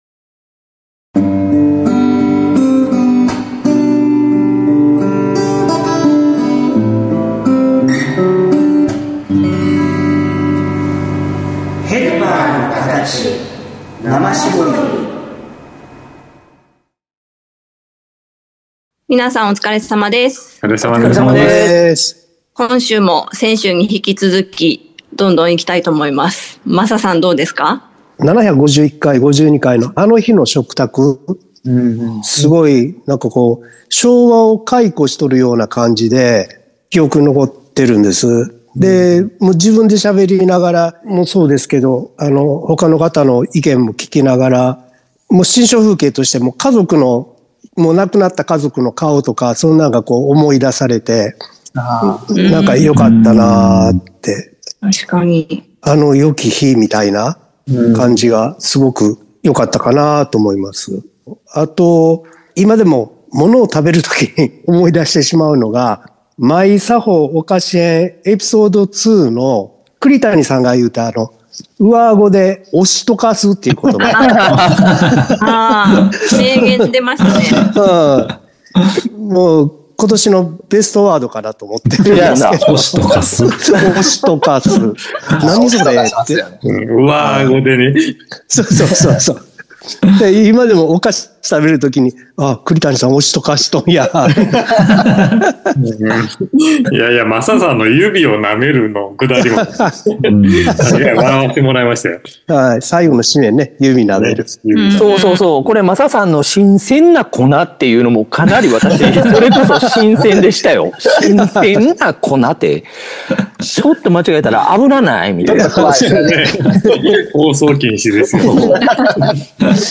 ＜今週のテーマ＞ 『生搾りアワード』への 前振り的配信の後編を配信。 一年を振り返りながら、 収録を回想するマッタリした トークをどうぞ御一聴下さい！